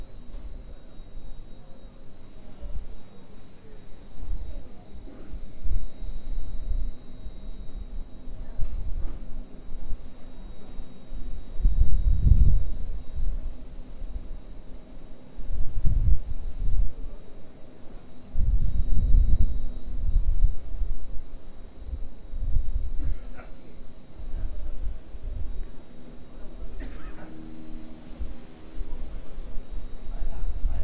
描述：Un ave grabada en Catamarca desconozco su nombre。一只鸟在阿根廷卡塔马卡录制
Tag: 鸟类 阿根廷 鸟纲 pajaros NATURALEZA 卡塔马卡 农村 性质